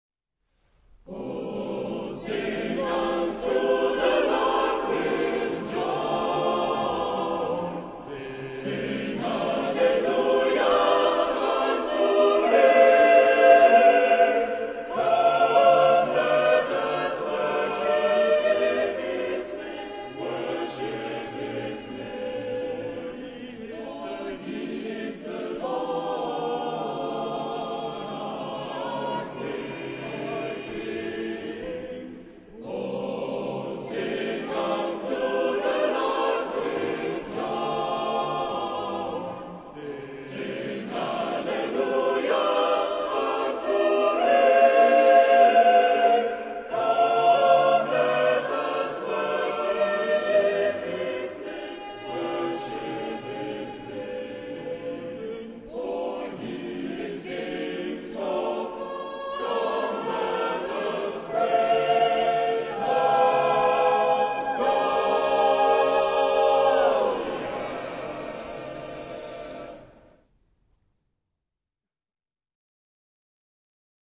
SAATB (5 voices mixed) ; Full score.
Close harmony.
Tonality: C major